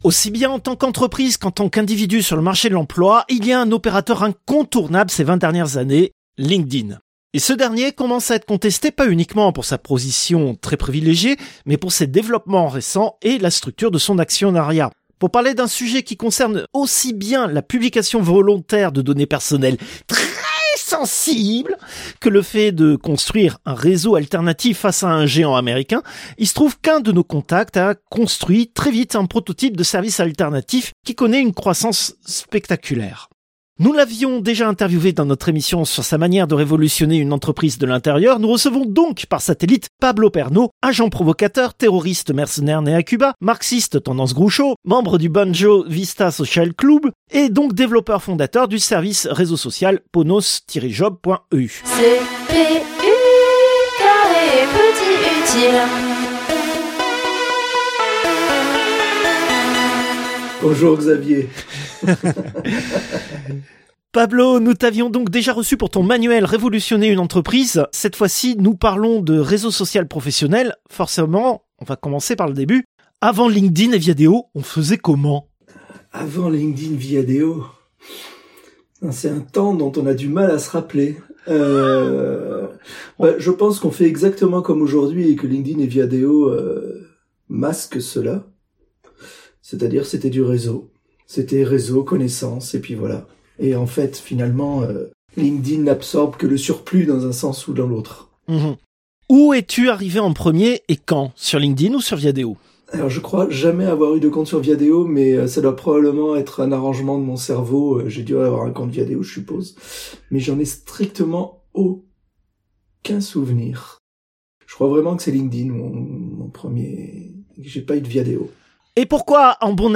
Interview diffusée dans l'émission CPU release Ex0237 : Réseau social professionnel.